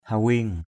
/ha-wi:ŋ/ (t.) choáng váng = avoir des éblouissements. hawing akaok hw{U a_k<K chóng mặt = être pris de vertige.